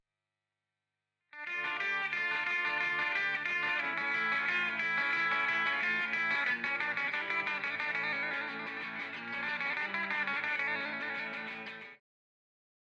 Αναλογική αισθητική στην ψηφιακή εποχή: Συγκριτική μελέτη αναλογικών και ψηφιακών τεχνικών στην ηχογράφηση και μίξη της ηλεκτρικής κιθάρας